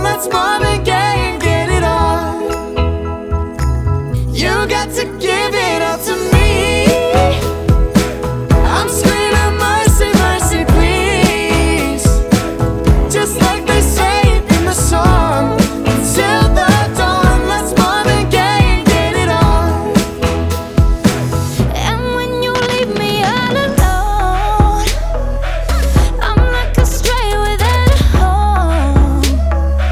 • Soul, Pop
doo-wop and soul song